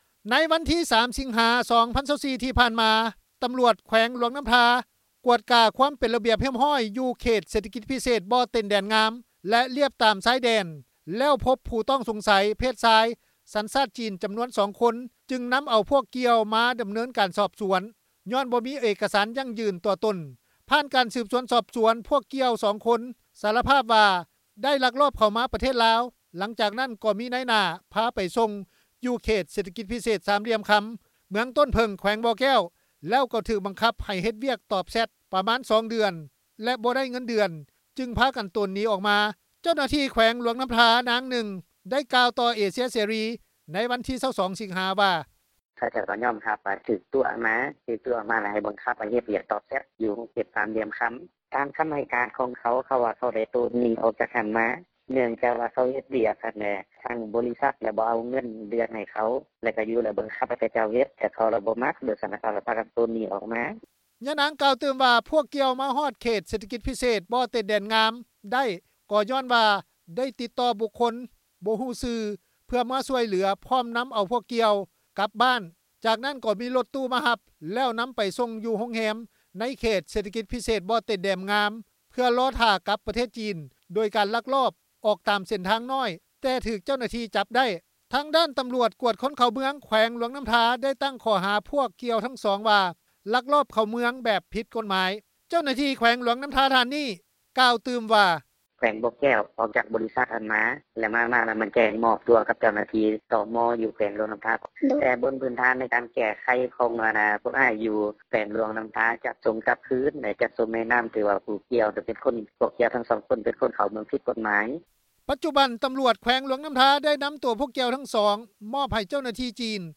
ເຈົ້າໜ້າທີ່ແຂວງຫລວງນໍ້າທາ ນາງນຶ່ງ ກ່າວຕໍ່ເອເຊັຽເສຣີ ໃນວັນທີ 22 ສິງຫາ ວ່າ:
ເຈົ້າໜ້າທີ່ແຂວງຫລວງນໍ້າທາອີກ ທ່ານນຶ່ງ ໄດ້ກ່າວວ່າ: